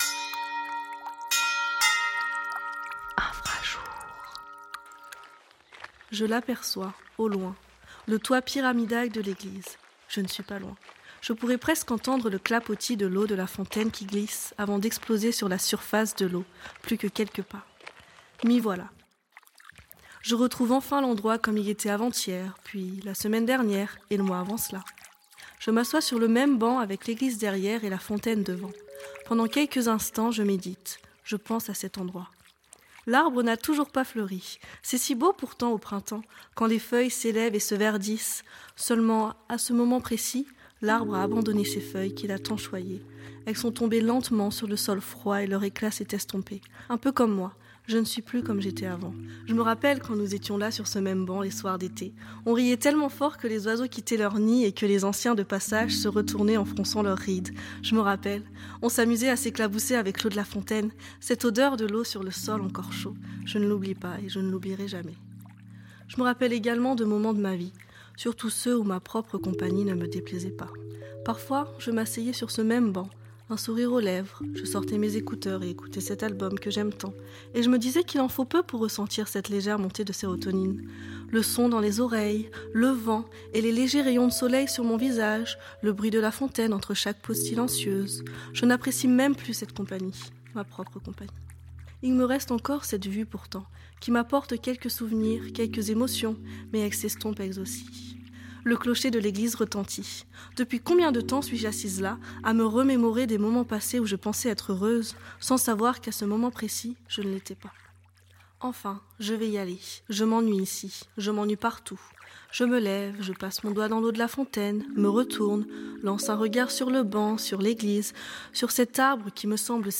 Effets sonores et musiques